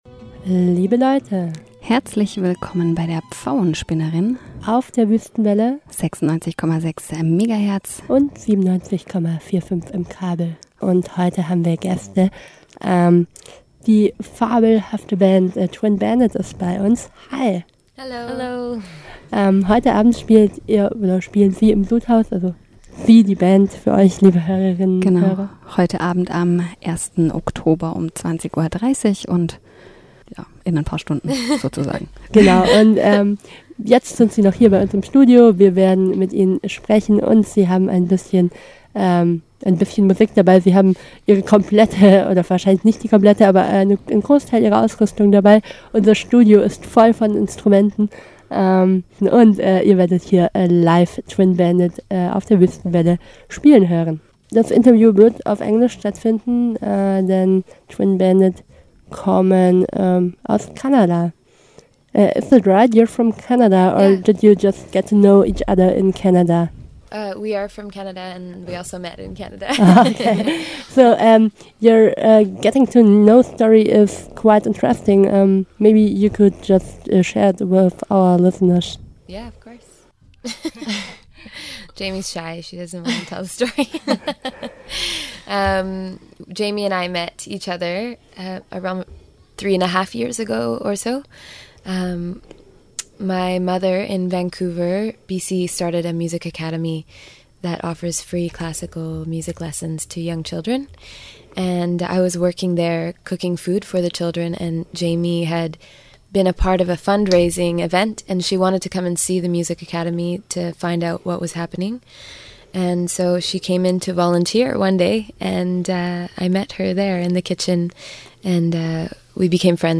Twin Bandit im Interview
Wenige Stunden davor besuchten sie uns, stellten sich unseren Fragen und spielten live im Studio.